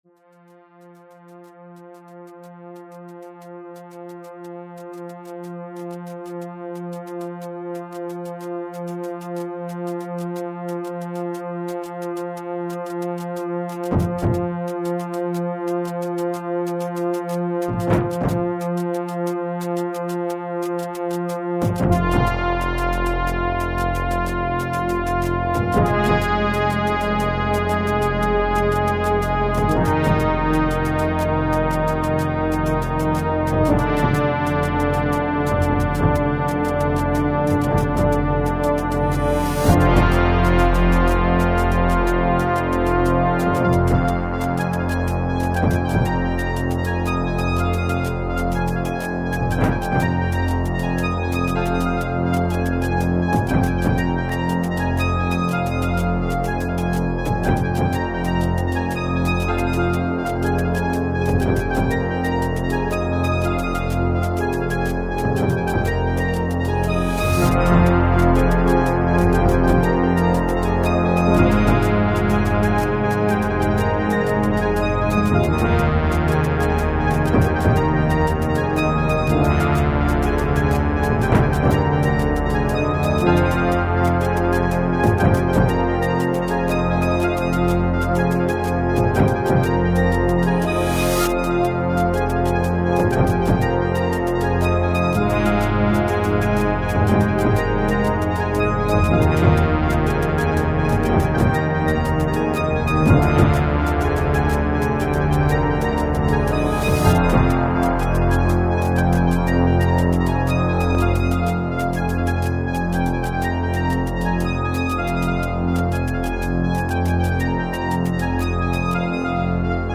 Tone Generator System Midi rack (1984)
demoAUDIO DEMO